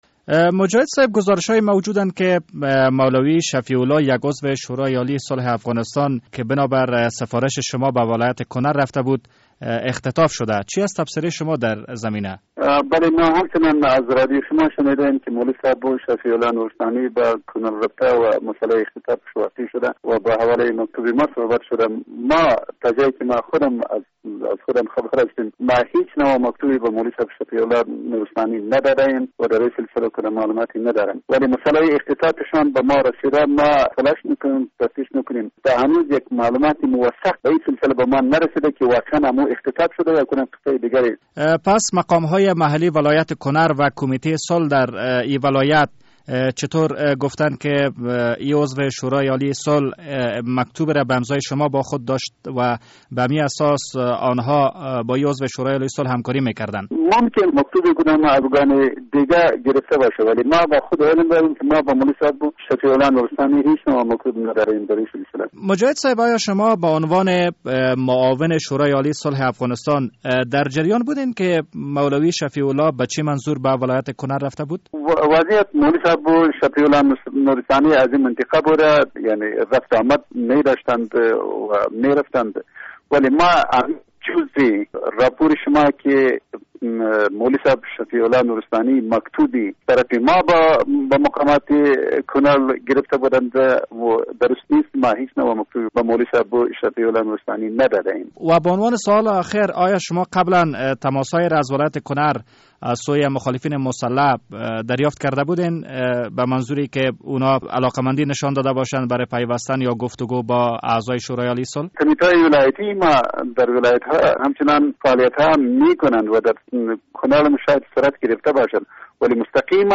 مصاحبه با عبدالحکیم مجاهد در مورد اختطاف یک عضو شورای عالی صلح